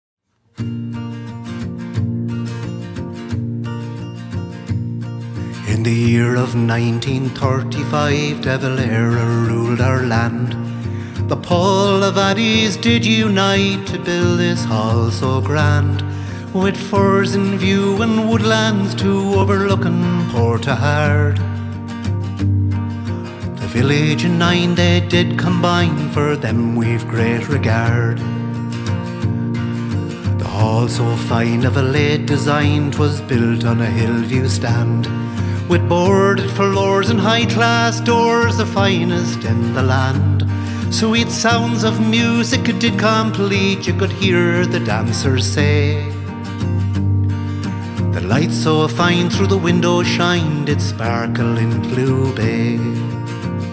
Guitar, Bodhrán, Harmonica, Vocals
Polished and beautifully performed